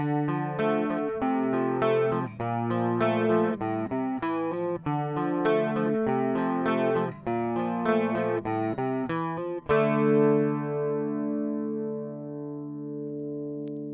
Запись + реамп clean гитар
Звук всё время плавает.
все точно в клик как семплер Вложения DI 3.mp3 DI 3.mp3 547 KB · Просмотры